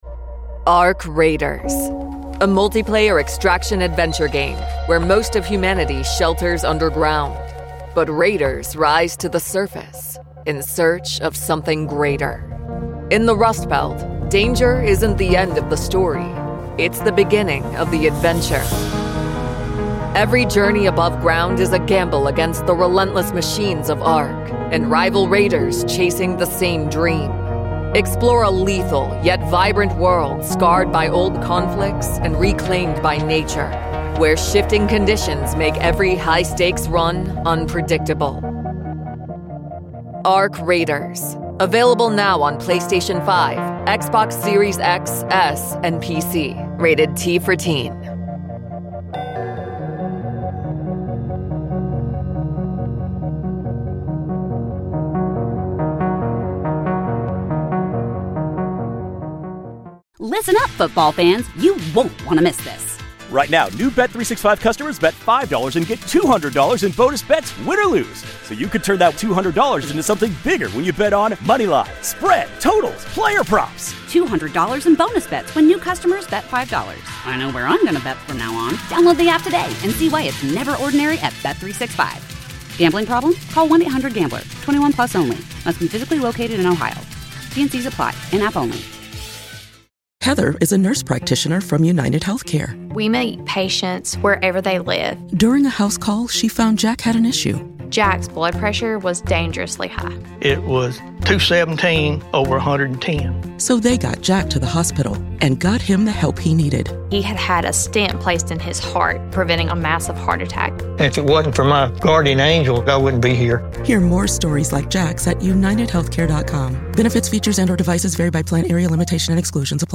An interview